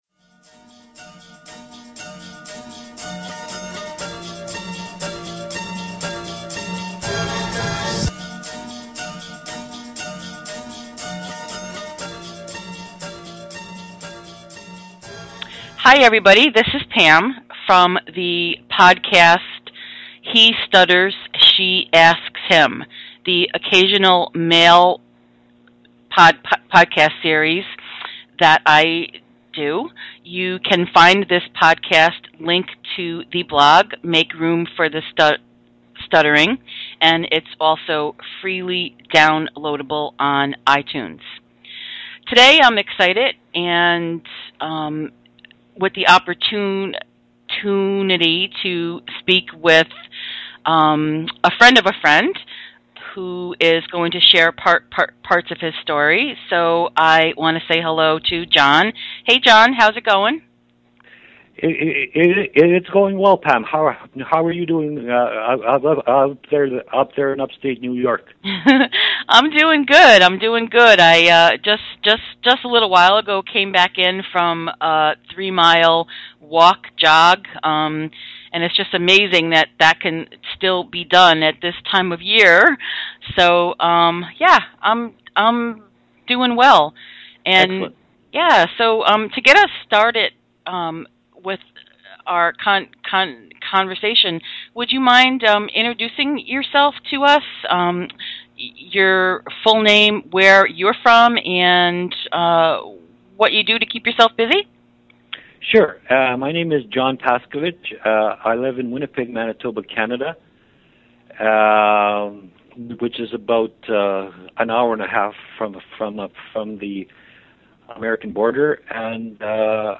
This was a great conversation, filled with lots of laughter and humor.